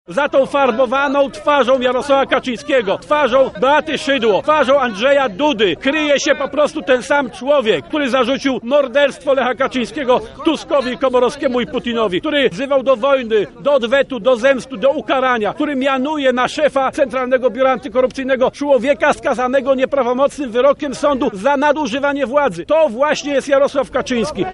Zrobił to tuż przed wystąpieniem Jarosława Kaczyńskiego na Placu Litewskim.
Wystąpieniu Palikota towarzyszyły gwizdy i okrzyki dezaprobaty ze strony zwolenników PiS.